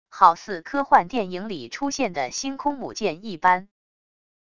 好似科幻电影里出现的星空母舰一般wav音频